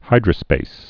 (hīdrə-spās)